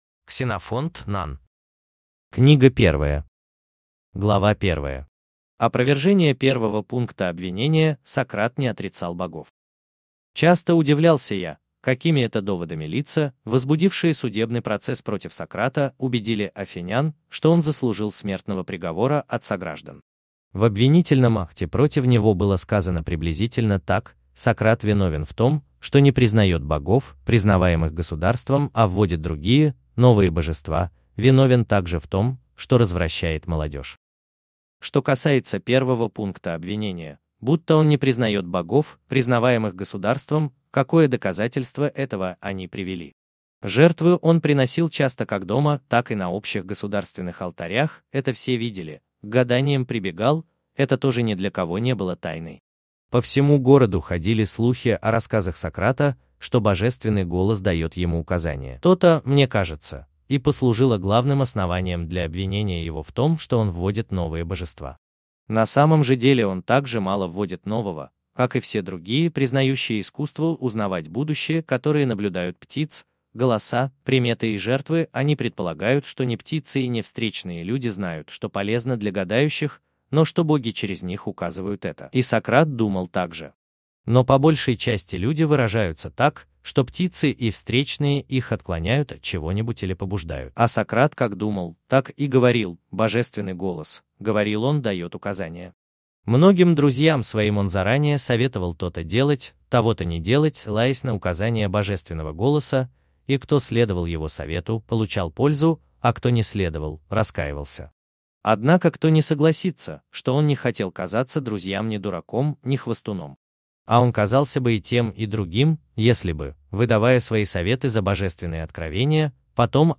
Аудиокнига Воспоминания о Сократе | Библиотека аудиокниг